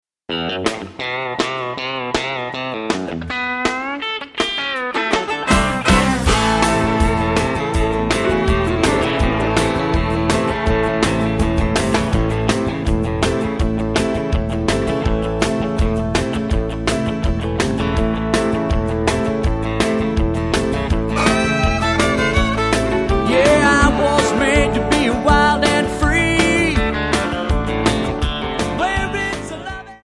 MPEG 1 Layer 3 (Stereo)
Backing track Karaoke
Country, Duets, 1990s